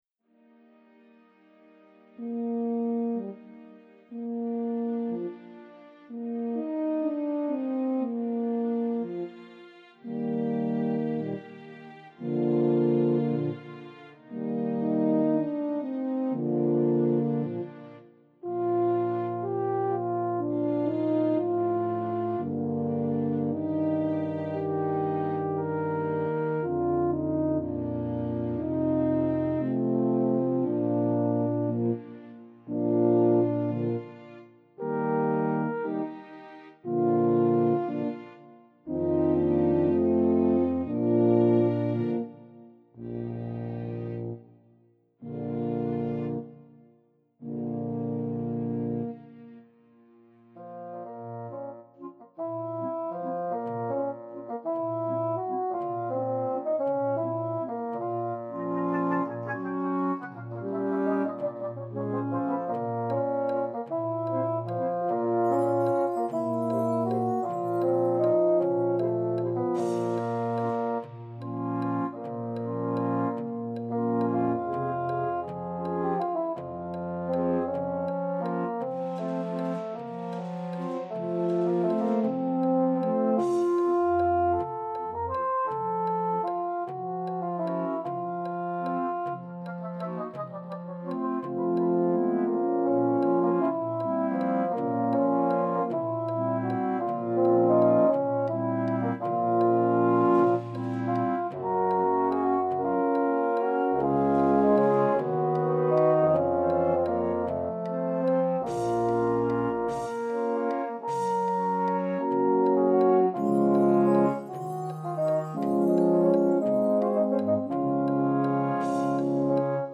realizzate principalmente con suoni campionati
con un quintetto d'archi
flauto
e fagotto